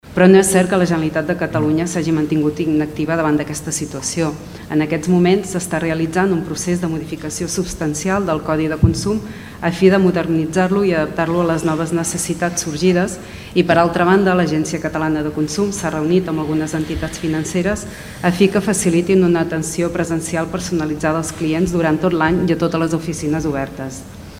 El Ple de l’Ajuntament de Tordera va aprovar una moció per controlar la digitalització dels serveis bancaris.
ERC+Gent de Tordera+Jovent Republicà afegeix que la Generalitat sí que ha actuat per evitar aquesta situació. Ho explica Marta Paset.